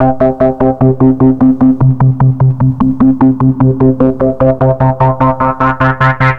09_The_Throbbe_150_E.wav